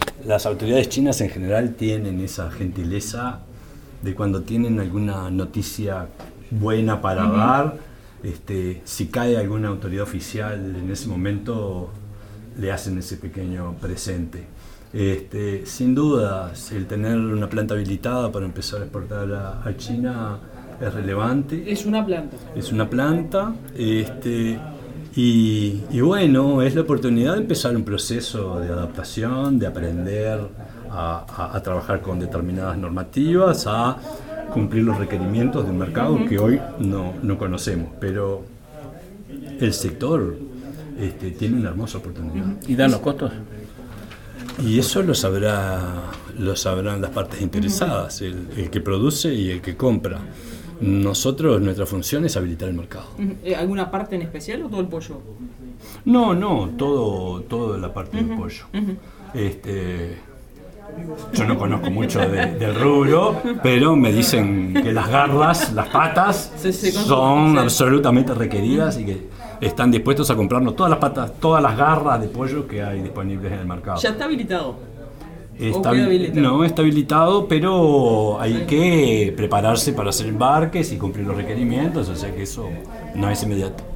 Presidente de INAC Gastón Scayola- habilitación aviar MP3
entrevistaaves.mp3